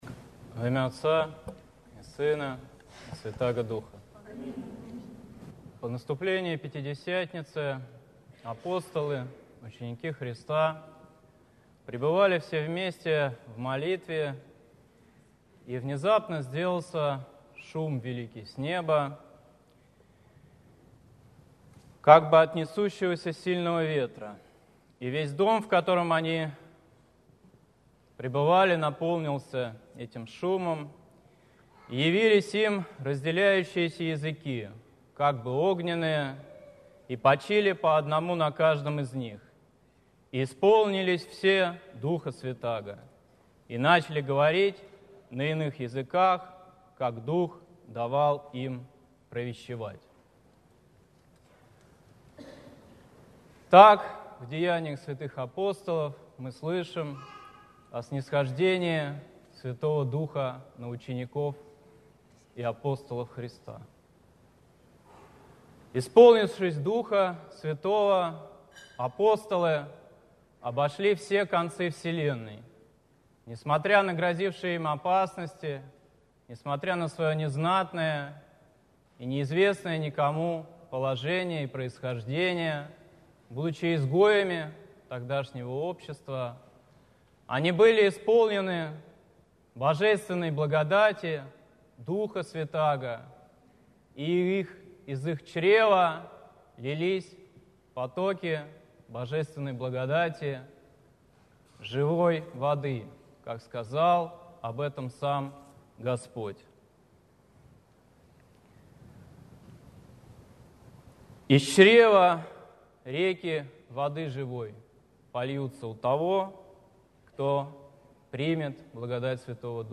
Псковская митрополия, Псково-Печерский монастырь